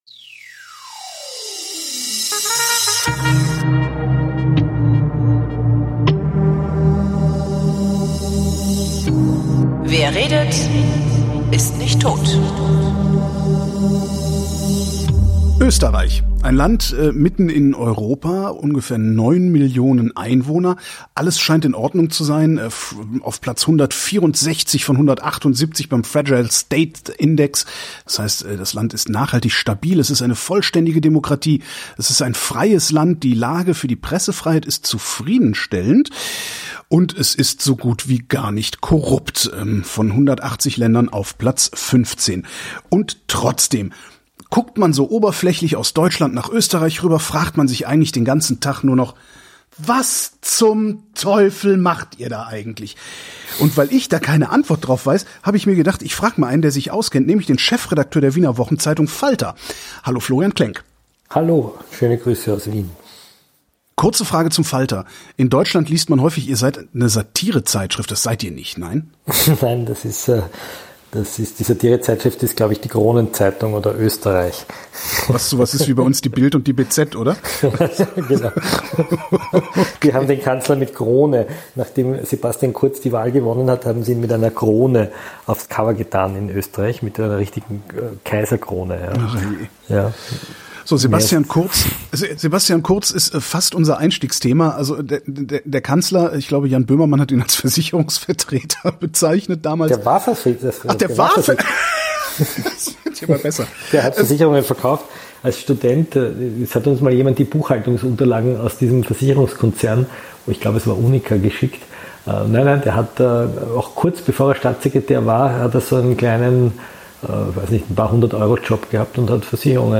Wenn man zu Beginn der 2020er Jahre in Richtung Österreich blickt, sieht man ein Land, das den Eindruck macht, ein einziger politischer Groß-Skandal zu sein. Wie es dahin gekommen ist, habe ich mir kurz von Florian Klenk erklären lassen.